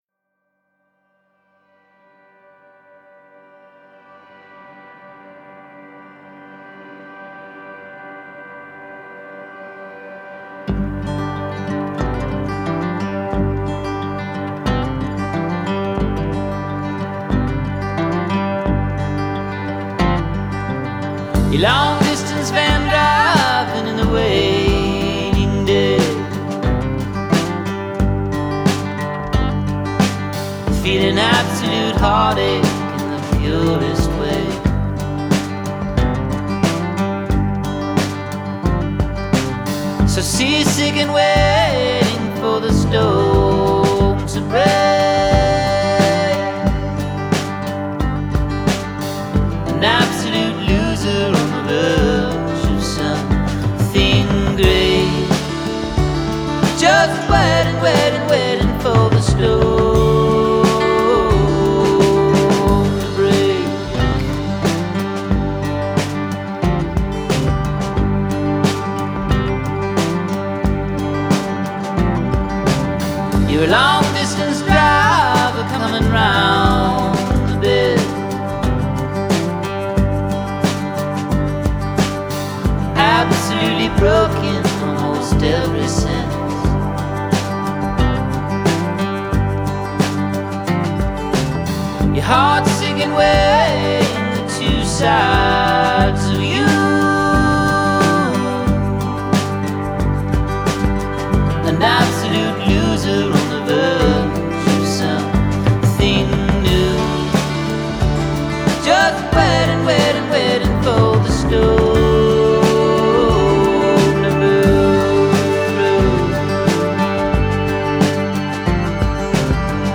showcasing a full band sound.
is a slow burn hook